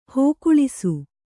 ♪ hōkuḷisu